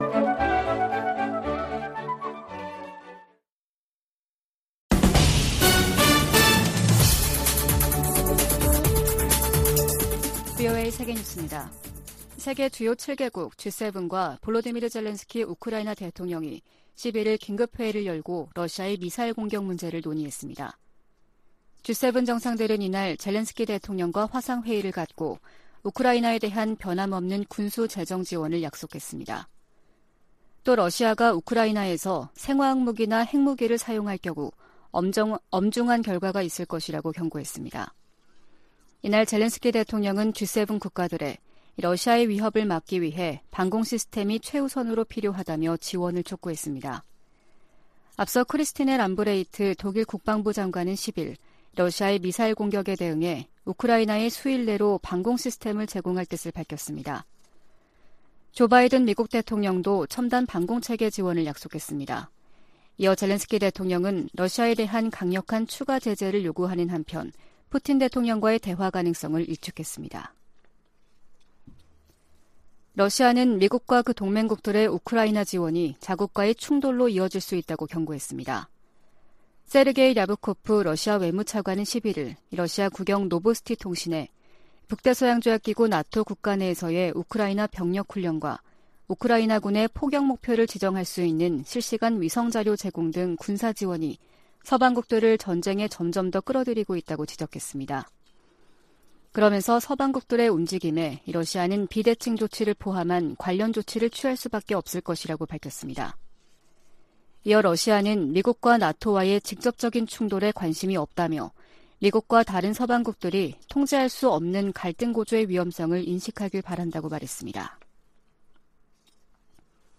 VOA 한국어 아침 뉴스 프로그램 '워싱턴 뉴스 광장' 2022년 10월 12일 방송입니다. 북한이 최근 핵전쟁 훈련을 벌인 것으로 확인되면서 향후 전술핵탄두 실험을 할 가능성이 높아졌다는 관측이 나오고 있습니다.